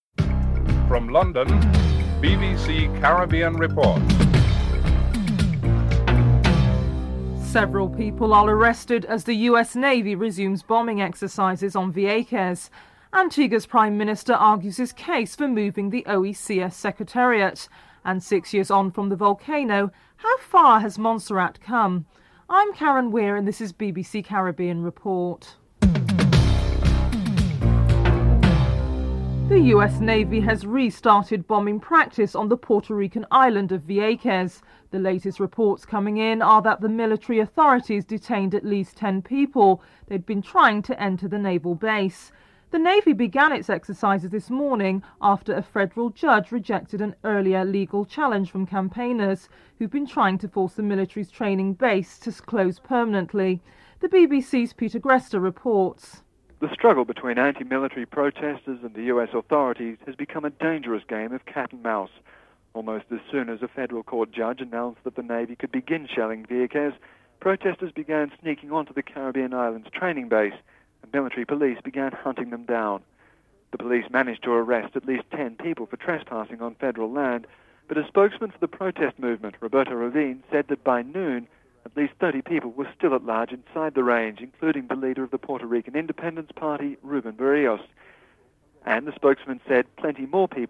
1. Headlines (00:00-00:29)
5. Six years on from the volcano how far has Montserrat come? Governor Tony Abbott is interviewed (12:22-15:43)